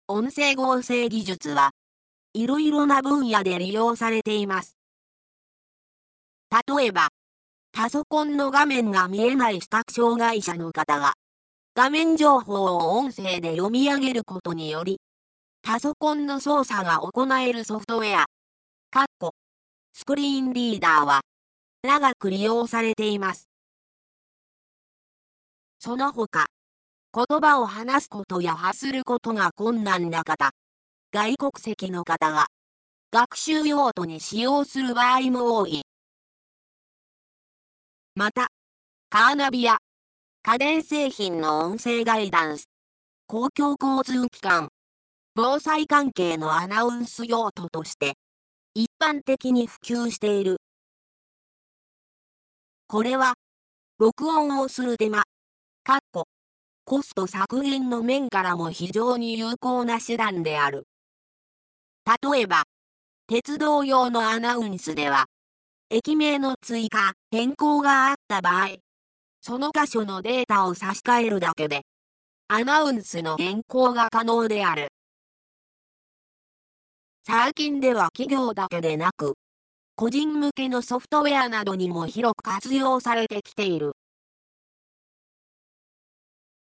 RealNarrators 3はパワーポイント(PowerPoint)を合成音声で読み上げ、スライドショー等の動画用コンテンツを作成するソフトウェアです
高品質日本語版　（女性音声サンプル）
アキラ叔母。男まさりな性格。